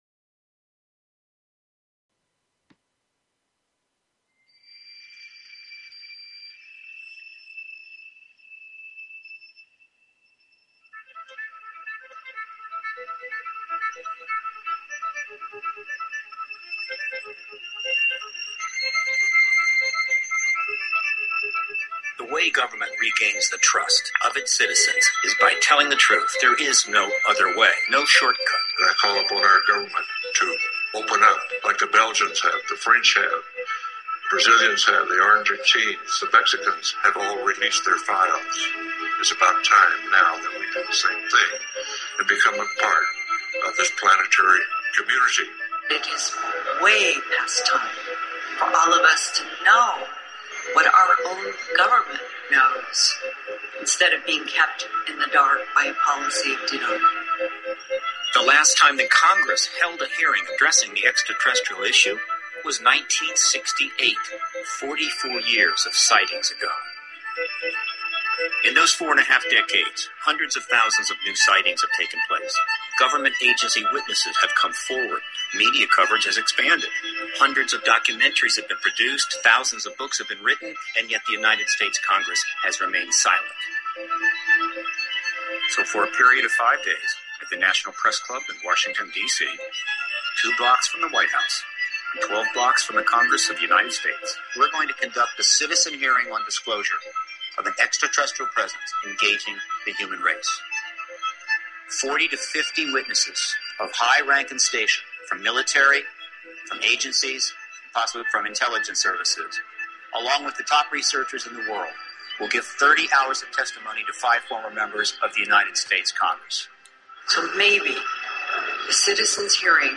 Talk Show Episode, Audio Podcast, Galactic_Roundtable and Courtesy of BBS Radio on , show guests , about , categorized as